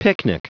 Prononciation du mot picnic en anglais (fichier audio)
Prononciation du mot : picnic